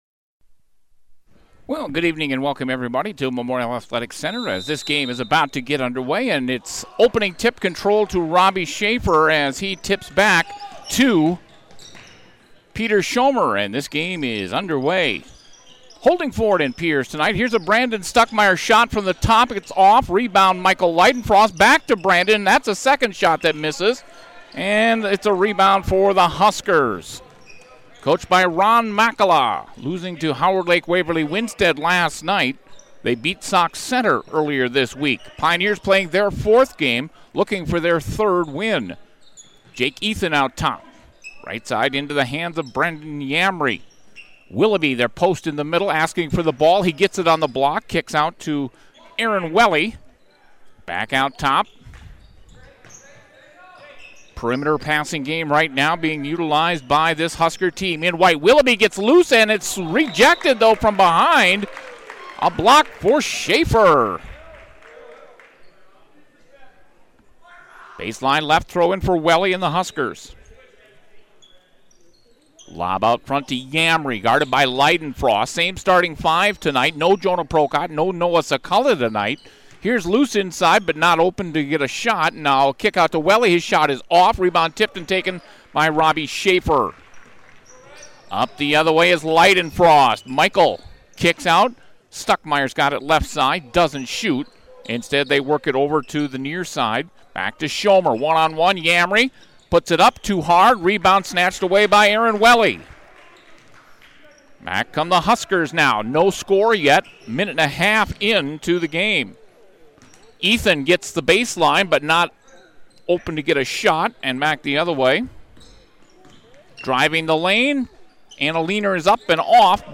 Pierz Pioneers vs Holdingford Huskers Boys Basketball 2020